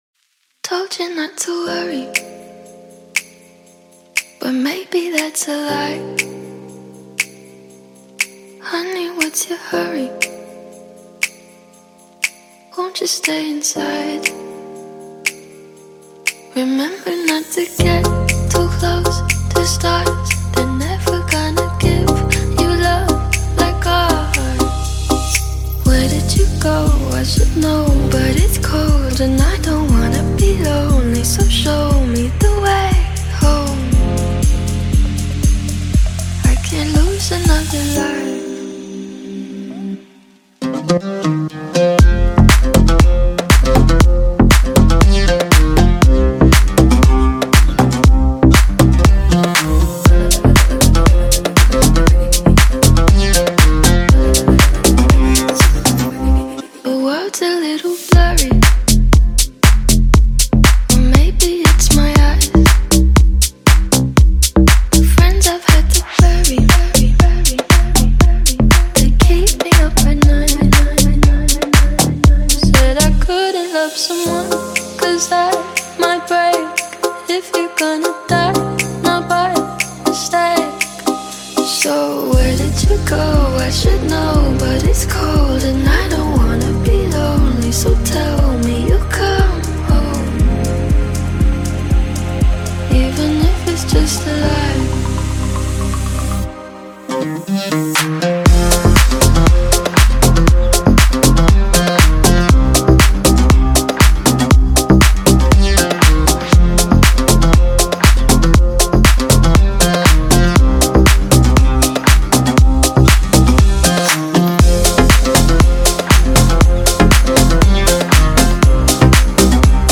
ریمیکس آهنگ 8 بعدی